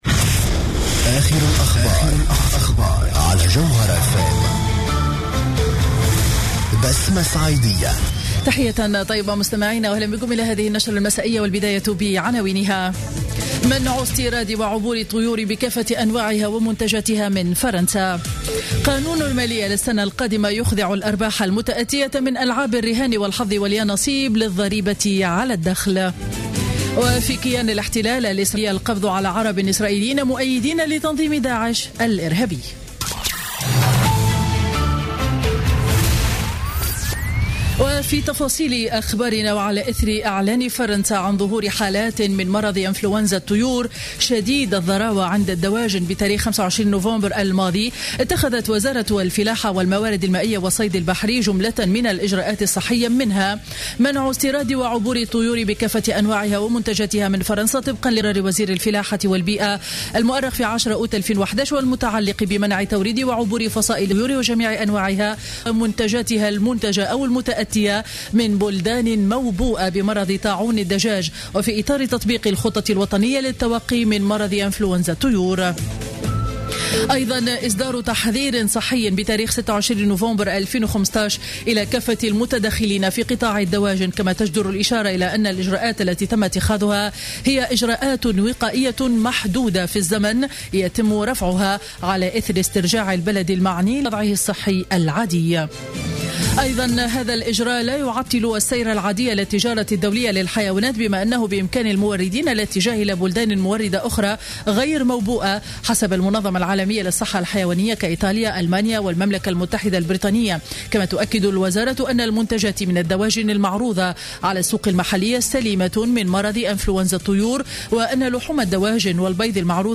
نشرة أخبار السابعة مساء ليوم الثلاثاء 08 ديسمبر 2015